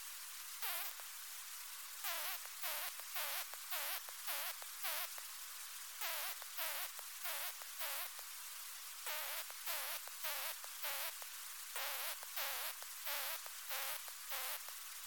Laboraudioaufnahme. 1.7.1988, 26°C, Position-detection lamp.